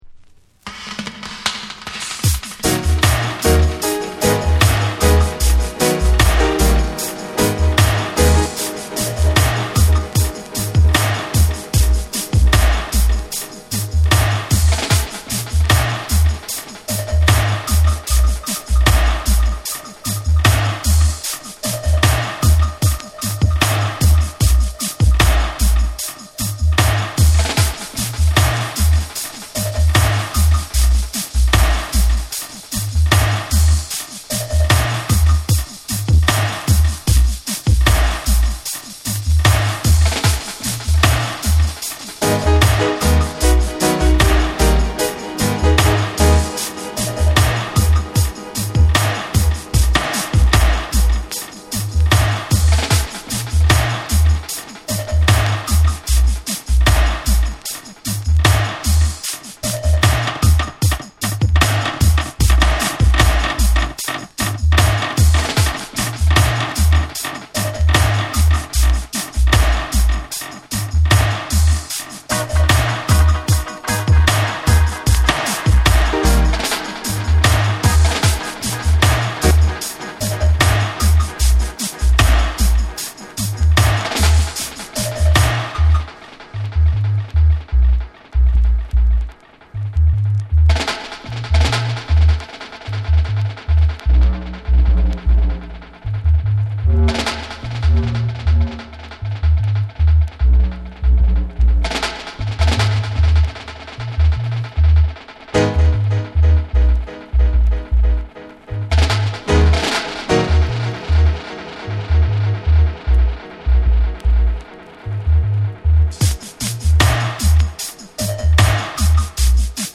ヘヴィなベースラインとディープなスピリットが交錯するルーツ・レゲエ/ダブ！
REGGAE & DUB